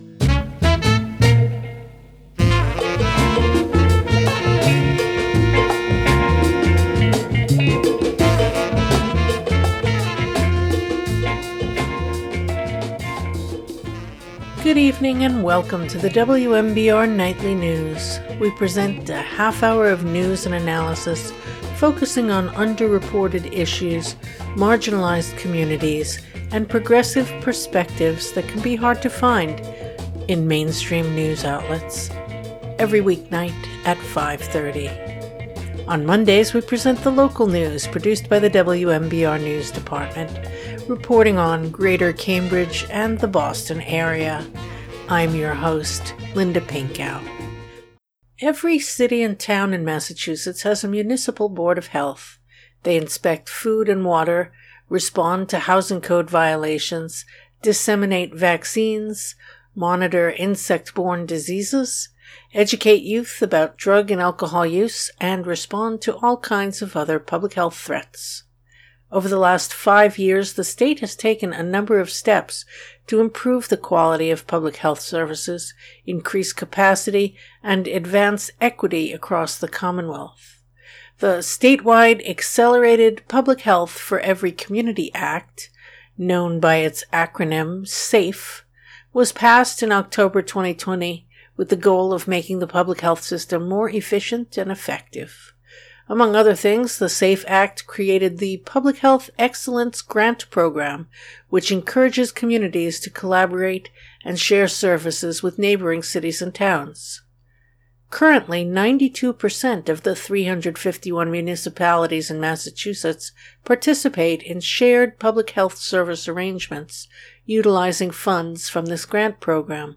Interview 10/27/2025: Public Health Excellence Day at the State House. 5 Year Lookback Guidance for the Future